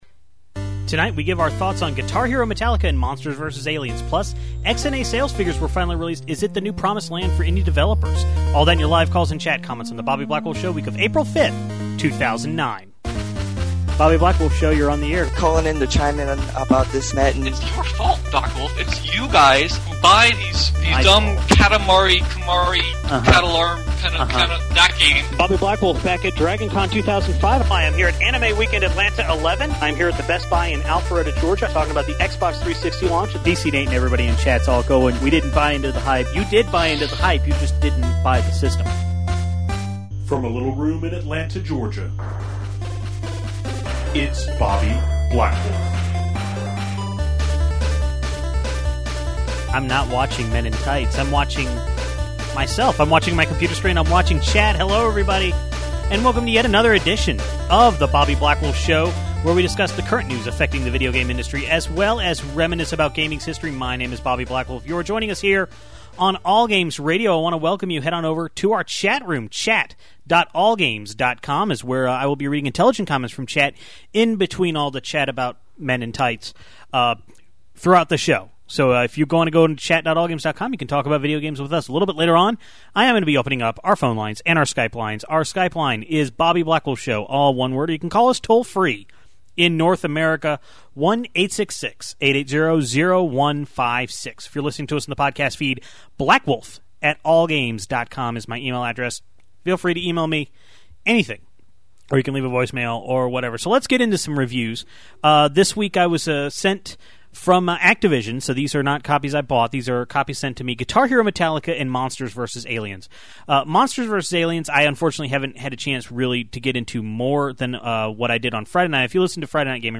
Microsoft finally released sales data to XNA creators - is it really the promised land? Then we take calls about PS1 classics on the PlayStation Store and how people outside Japan have been getting the shaft.